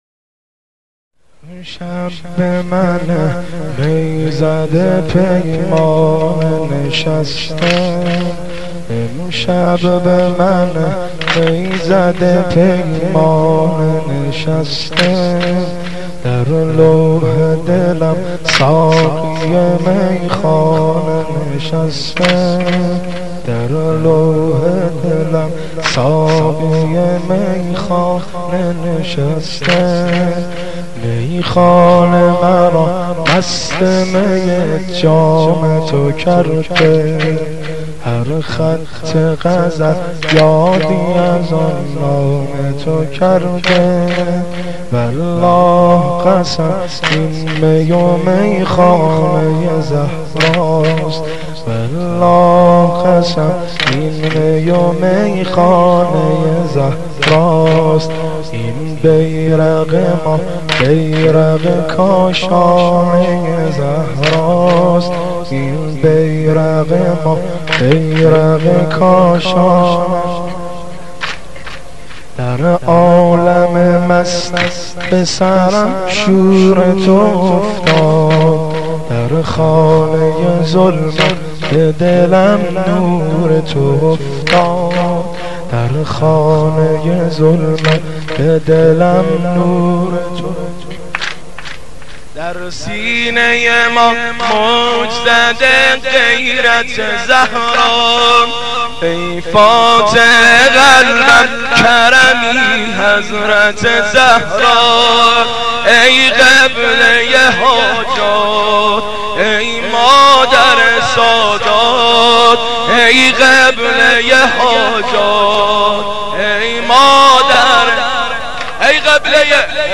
مداح
قالب : سنگین